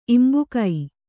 ♪ imbukai